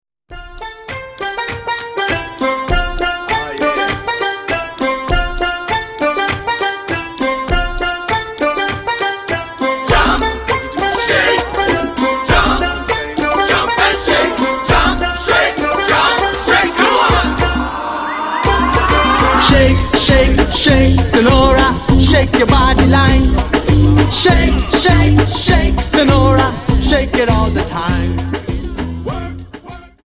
Dance: Samba Song